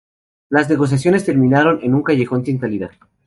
Pronounced as (IPA)
/kaʝeˈxon/